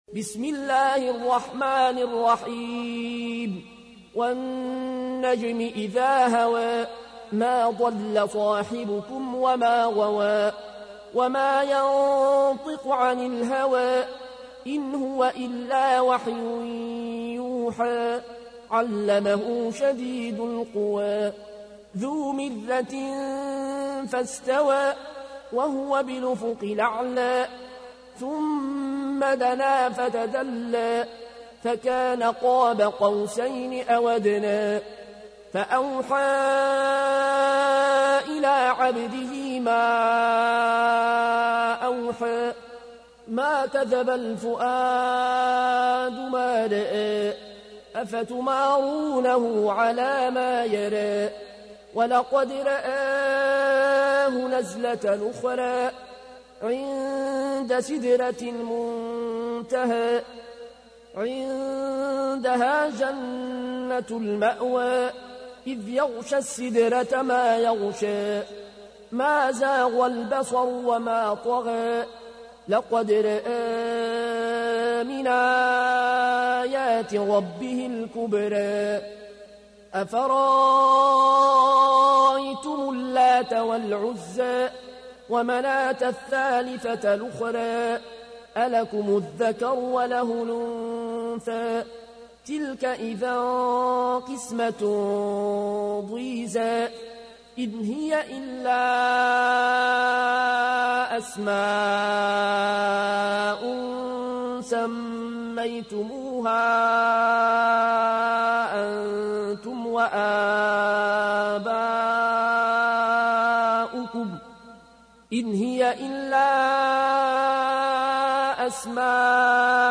تحميل : 53. سورة النجم / القارئ العيون الكوشي / القرآن الكريم / موقع يا حسين